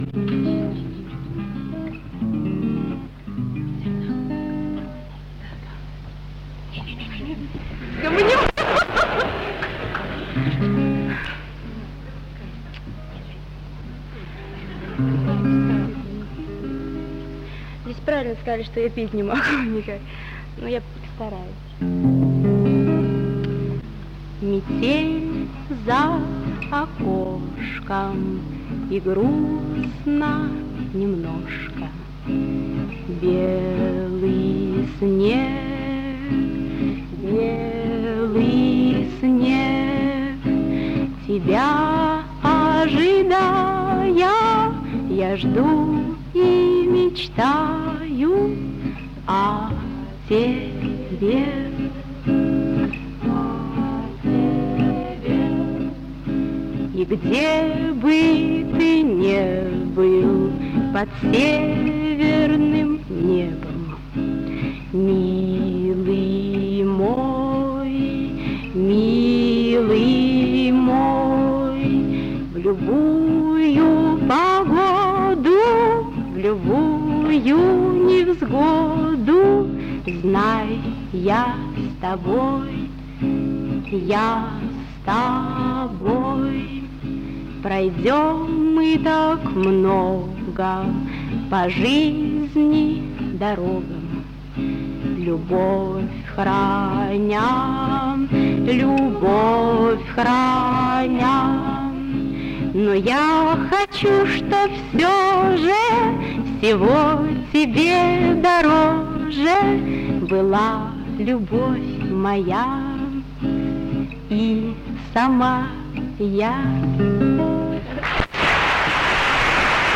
Аудиозаписи Третьего Московского конкурса студенческой песни
ДК МЭИ. 7 декабря 1961 года.